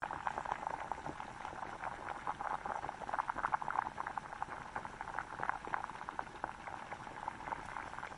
High Boil Pot
High Boil Pot is a free sfx sound effect available for download in MP3 format.
yt_hUCTmlz-ApM_high_boil_pot.mp3